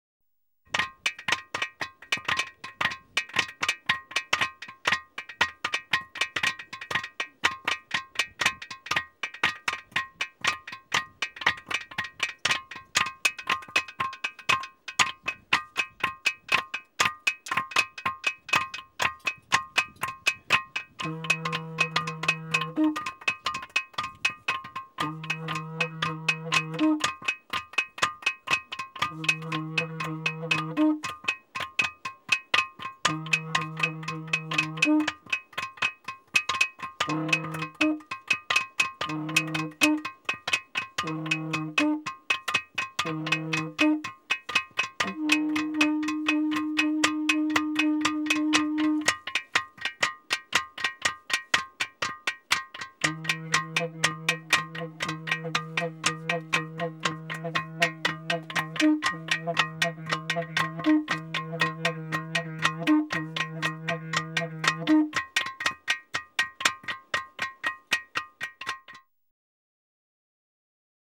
Rock gongs, lithophones, or ringing rocks are found worldwide and are large, often stand -alone rocks which have an inherent ringing sound, released when struck by a smaller stone.
played by some visiting Druids to the island.
19-druid-rock.m4a